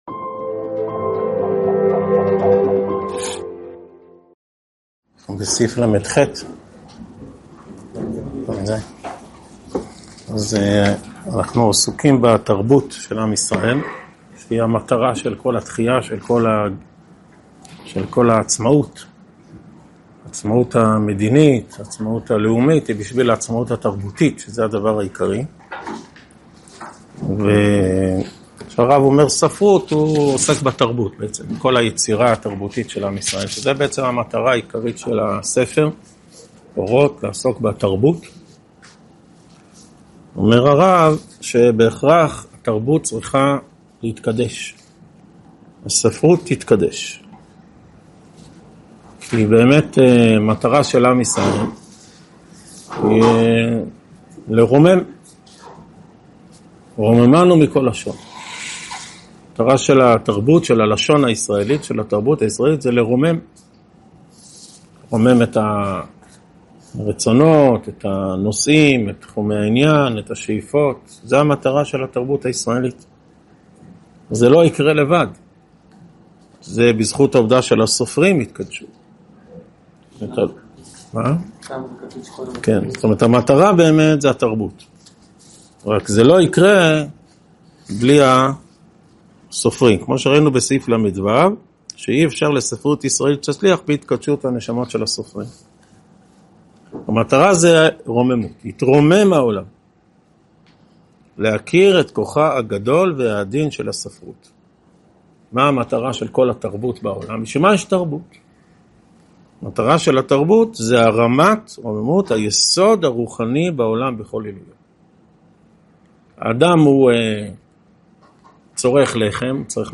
שיעור 41 מתוך 61 בסדרת אורות התחיה
הועבר בישיבת אלון מורה בשנת תשפ"ה.